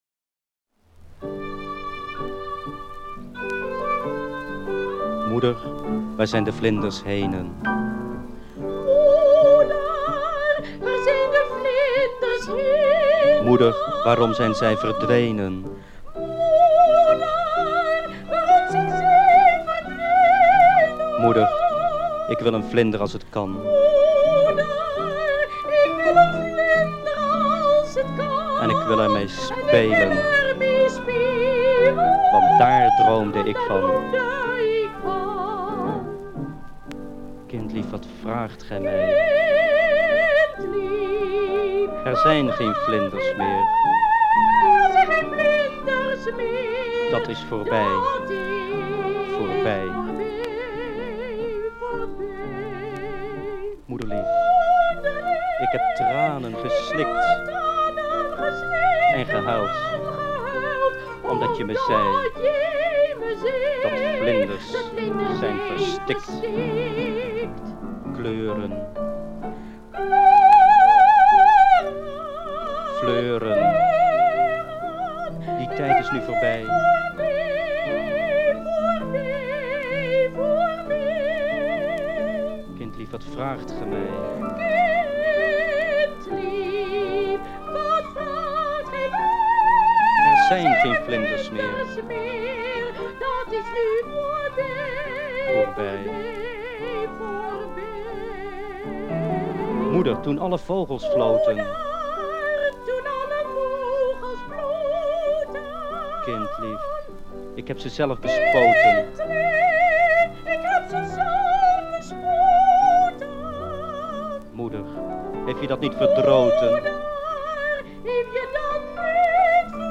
Smartlap